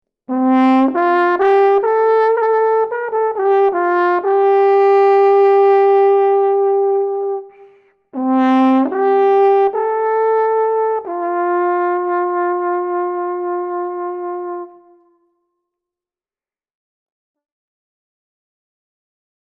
Le cor des Alpes (Alphorn)
Le son est produit par la vibration des lèvres sur une embouchure, comme le cor ou la trompette.
On trouve généralement des instruments accordés en Fa ou en Fa#.
son Alphorn melodie 2.mp3